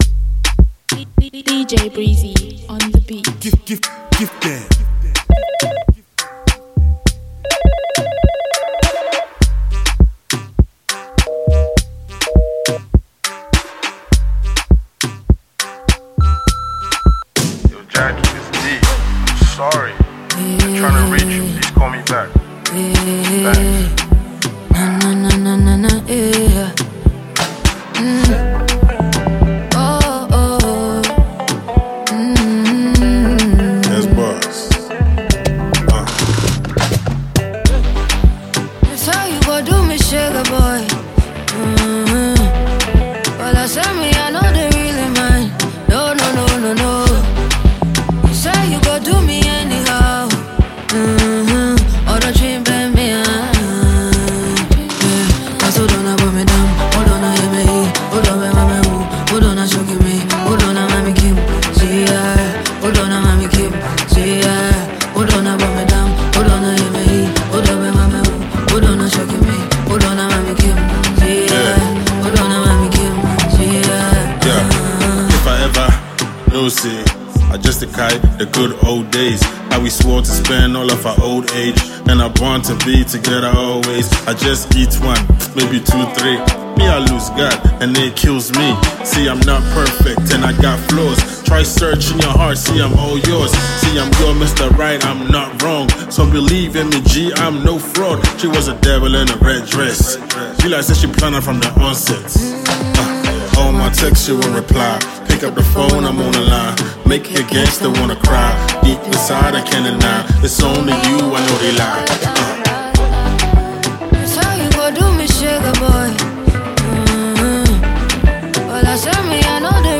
Ghana Music Music
Ghanaian rapper